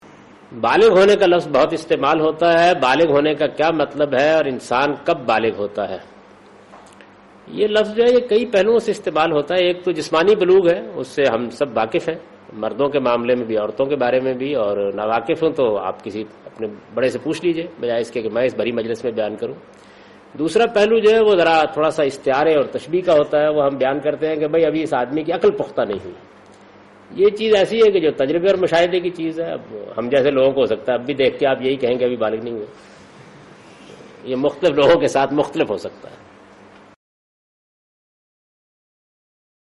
Javed Ahmad Ghamidi responds to the question 'What is age of Maturity '?